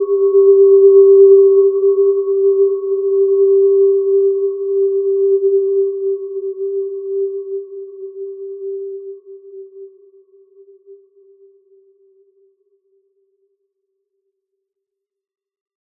Gentle-Metallic-3-G4-p.wav